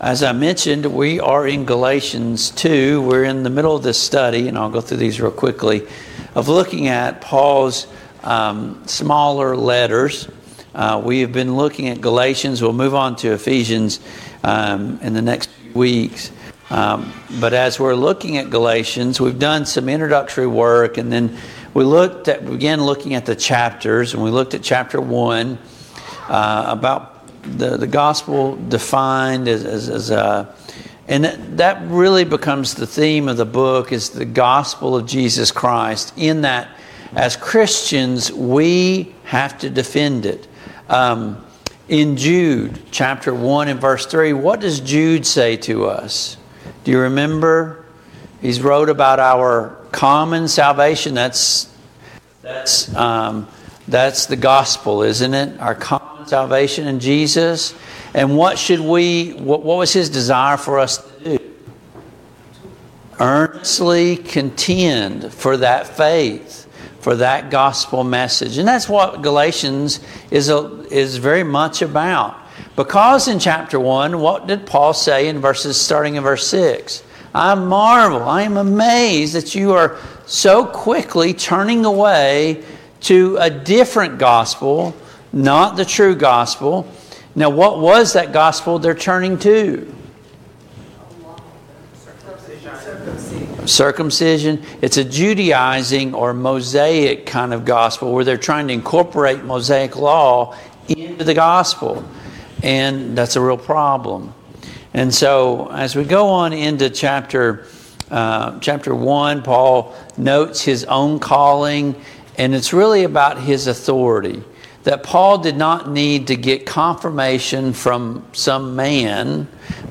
Passage: Galatians 2, Galatians 2:9-14 Service Type: Mid-Week Bible Study